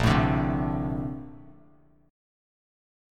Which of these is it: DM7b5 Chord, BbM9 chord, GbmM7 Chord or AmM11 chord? BbM9 chord